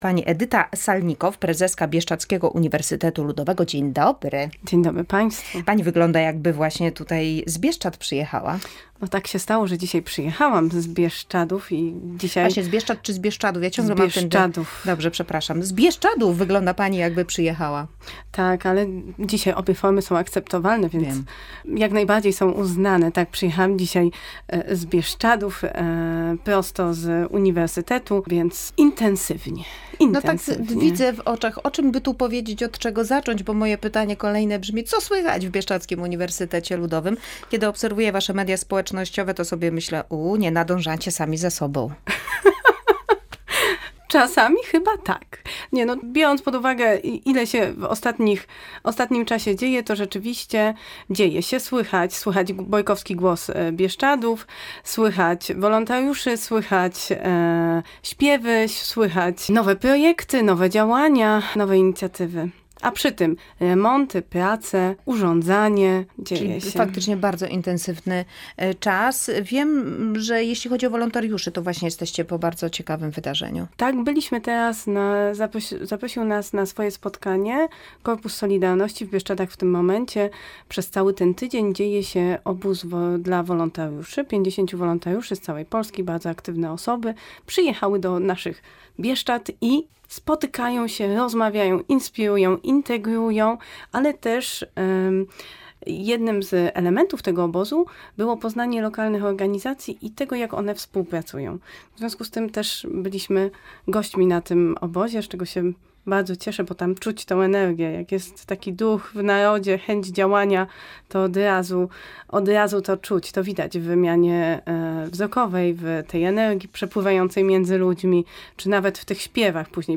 W naszym studiu